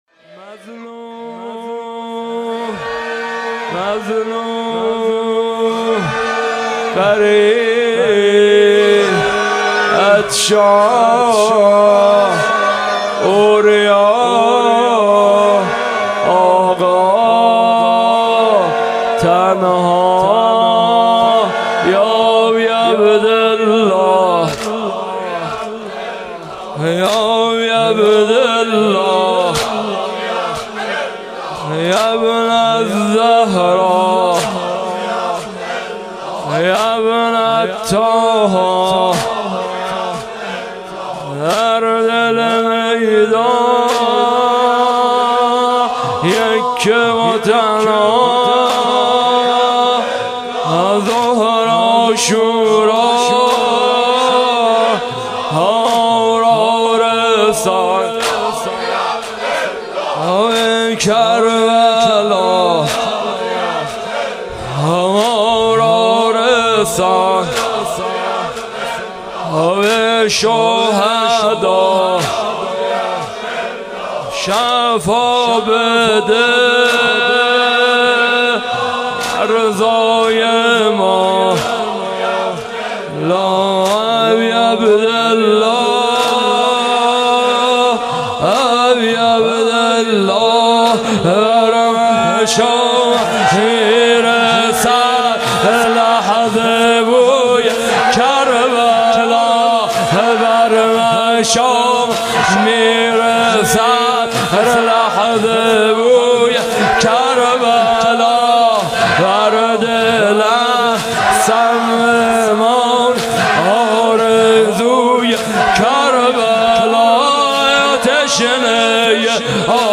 شب اول - واحد - یا اباعبدالله یابن الزهرا